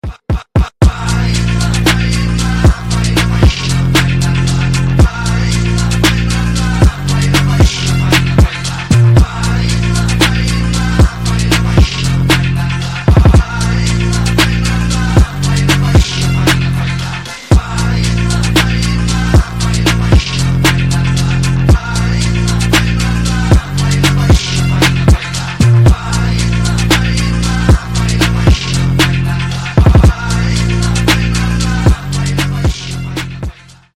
Громкие Рингтоны С Басами
Фонк Рингтоны
Танцевальные Рингтоны